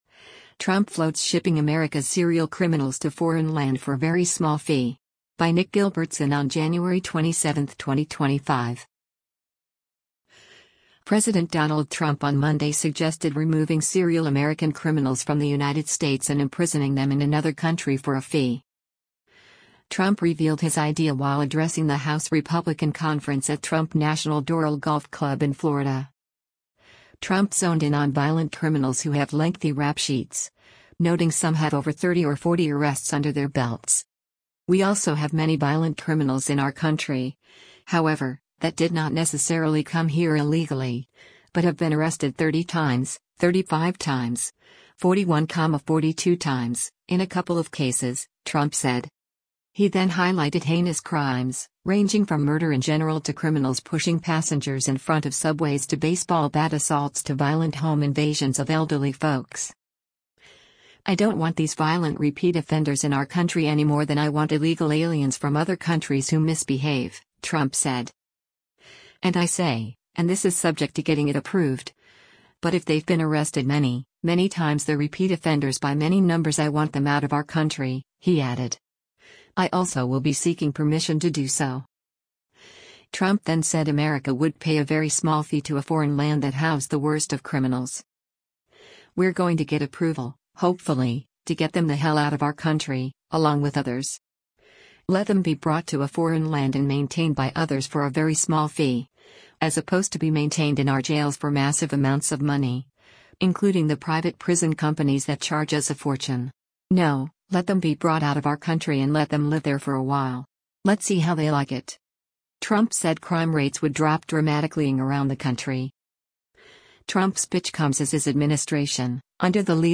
Trump revealed his idea while addressing the House Republican Conference at Trump National Doral Golf Club in Florida.